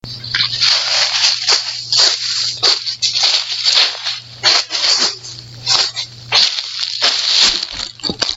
揉碎的叶子
描述：叶子沙沙作响